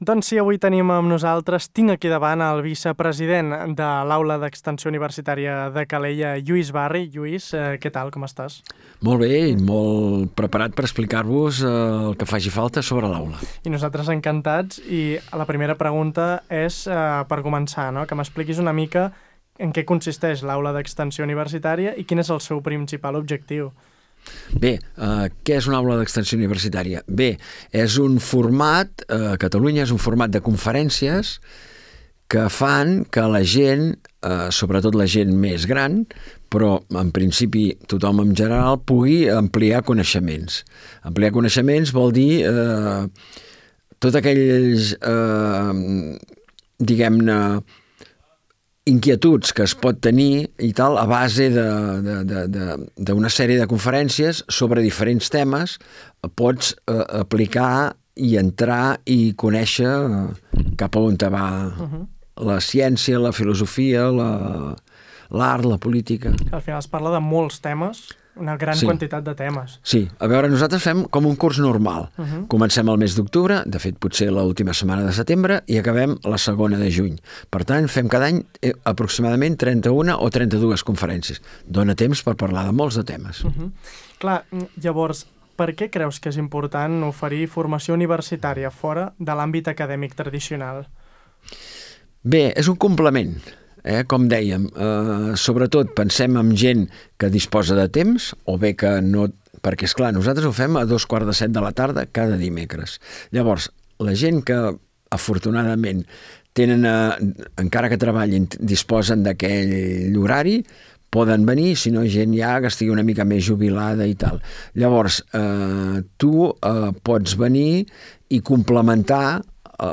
Una entrevista per conèixer de prop una iniciativa clau del teixit cultural de Calella.